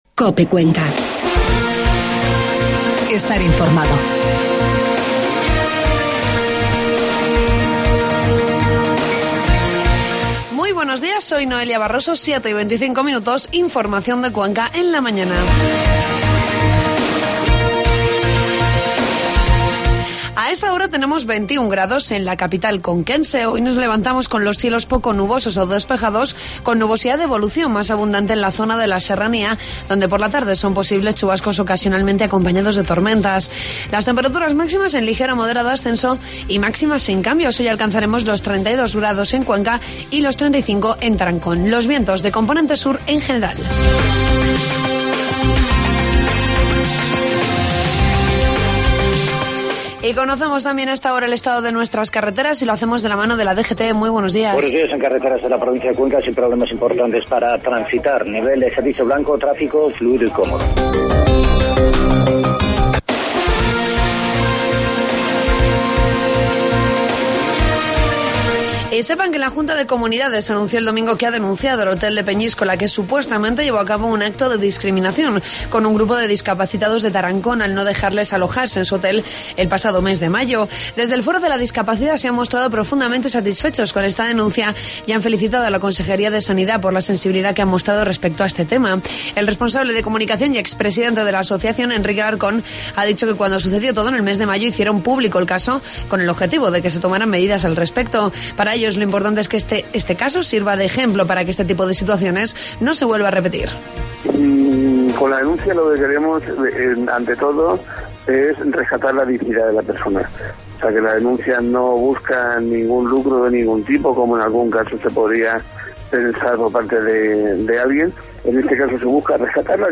Informativo matinal 23 de julio